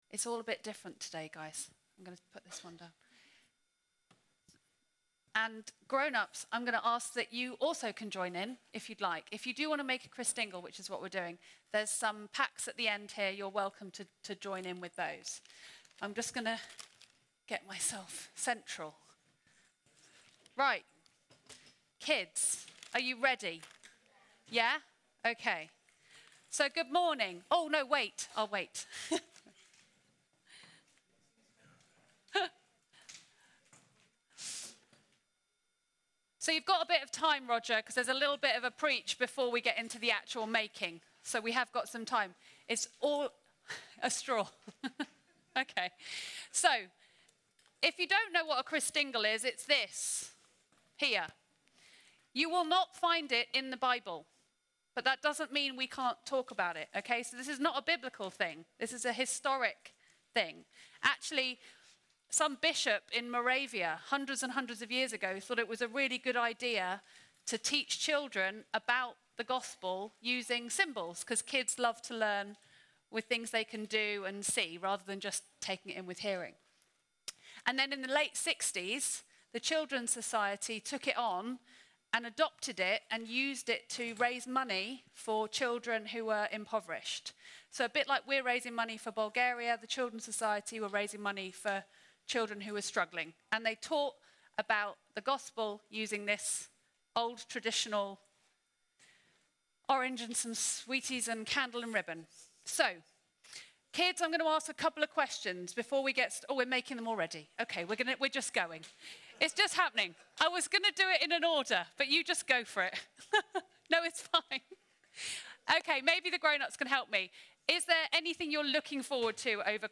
Sermons from Faithlife Church, Cambridge